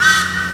TRAIN.WAV